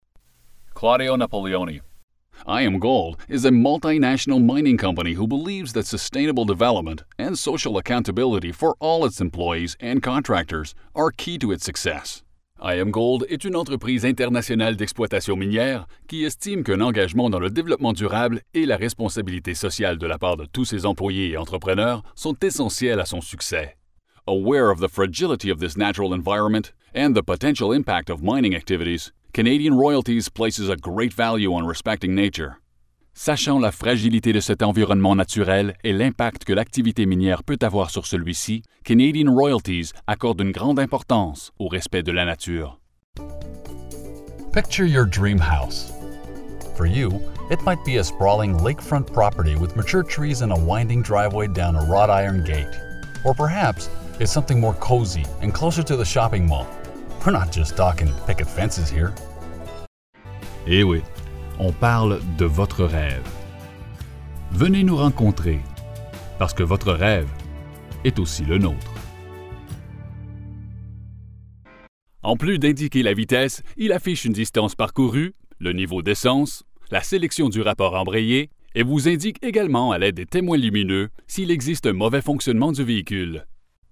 Voix Parfaitement Bilingue Canadien Francais et Anglais. Bilingual, English and French Canadian Male voice over (voice off) and narrator for adversting, documentaries and corporate videos
Sprechprobe: eLearning (Muttersprache):